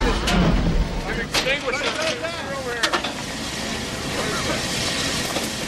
workers chattering with pickhammer.ogg
Original creative-commons licensed sounds for DJ's and music producers, recorded with high quality studio microphones.
[workers-chatterlng-sound-effect]_ngi.mp3